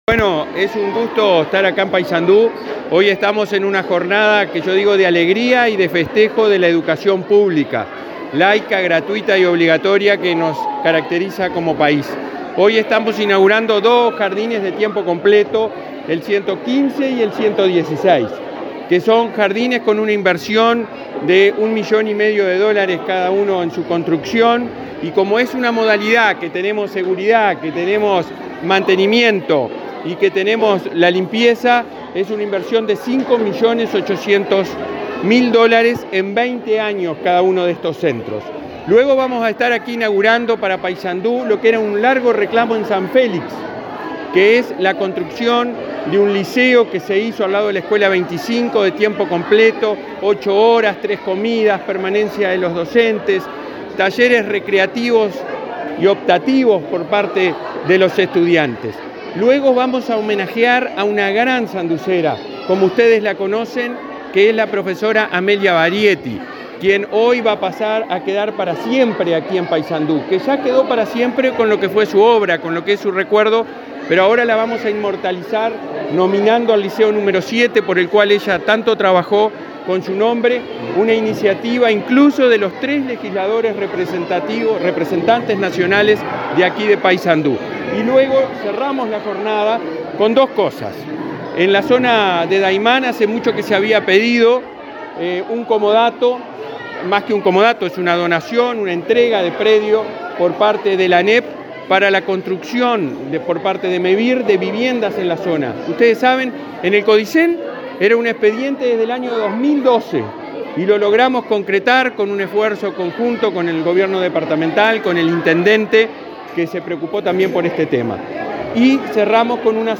Declaraciones del presidente de la ANEP, Robert Silva
El presidente de la ANEP, Robert Silva, dialogó con la prensa durante una recorrida por el departamento de Paysandú, donde inauguró obras en varios